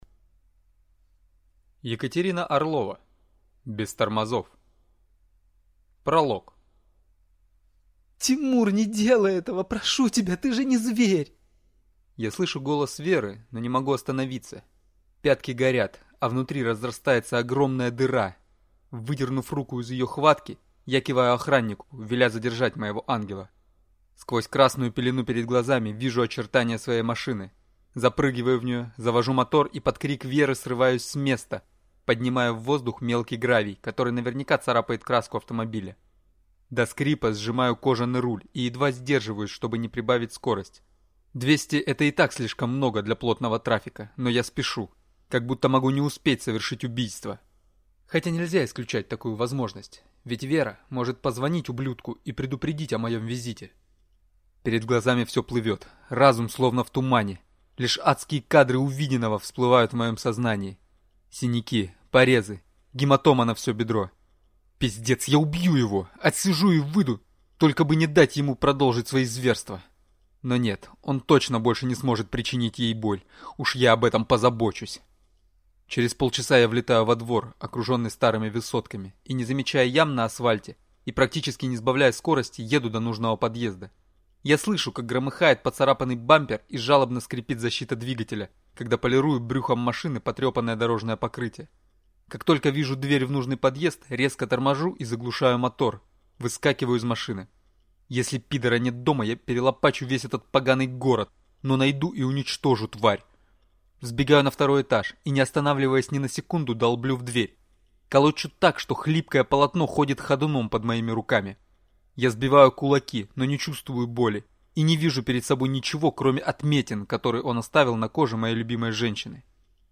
Аудиокнига Без тормозов | Библиотека аудиокниг